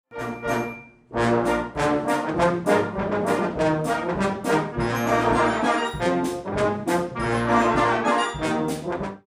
light German-style march